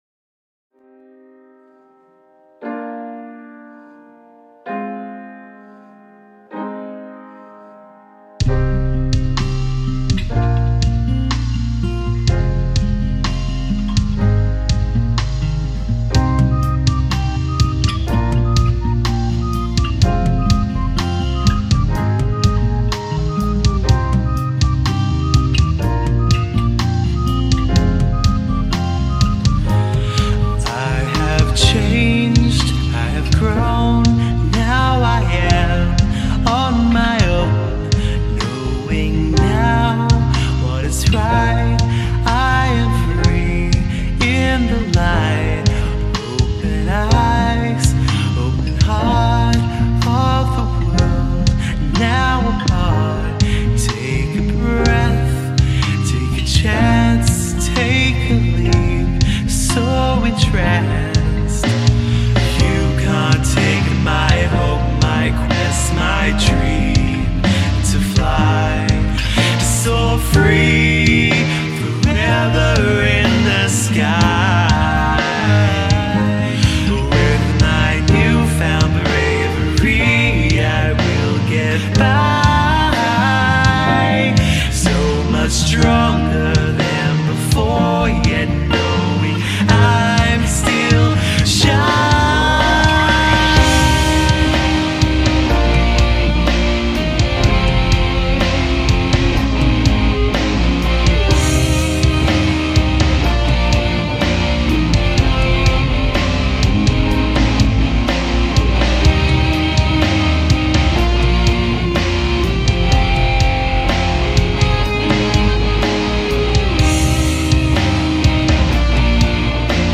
Piano
Heavy guitar
Bass